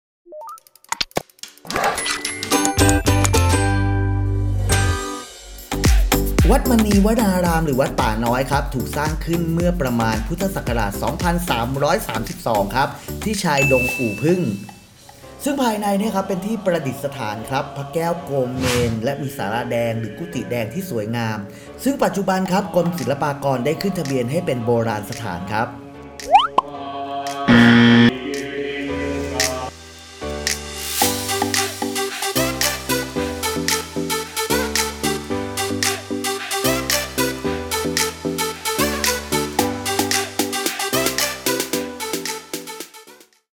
เสียงบรรยายภาพ
ad-วัดมณีวนาราม-อุบล.mp3